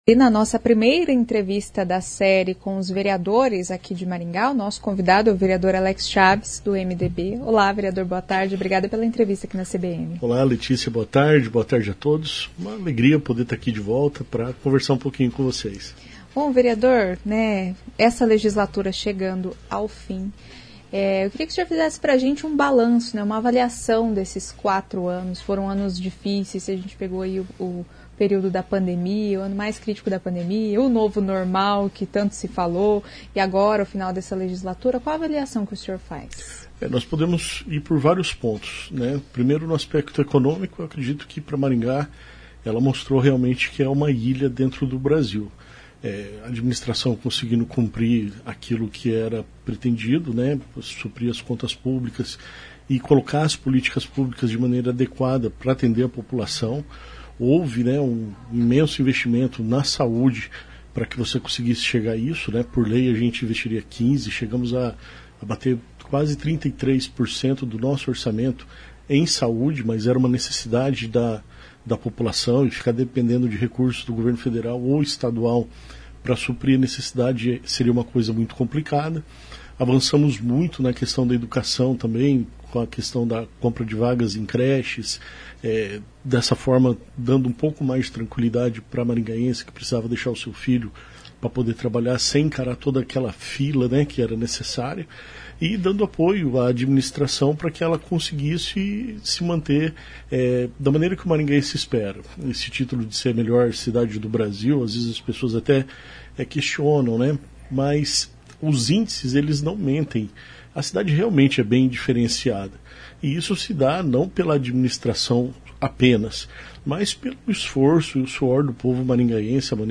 Nesta entrevista o vereador Alex Chaves (MDB) faz uma análise da gestão atual e pontos que precisam de mais atenção.